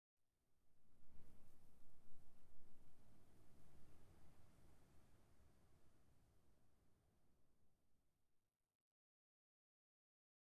latest / assets / minecraft / sounds / block / sand / wind11.ogg
wind11.ogg